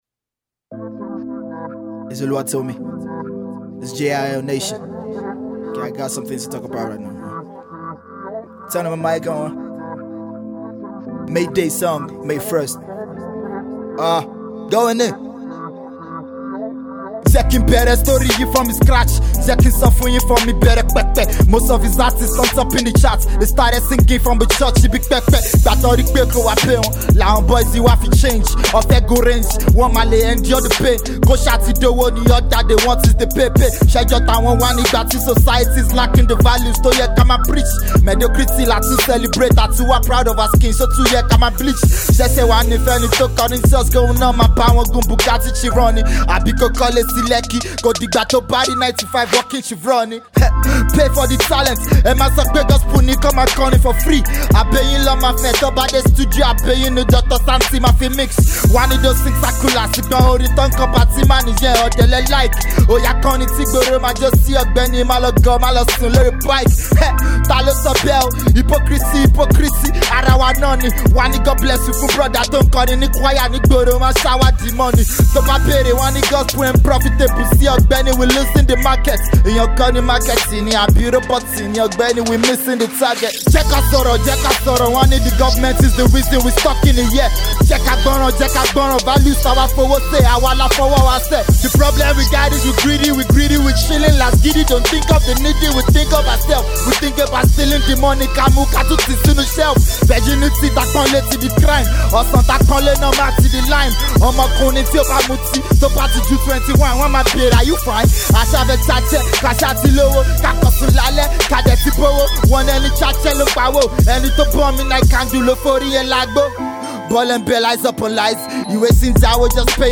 Christian rapper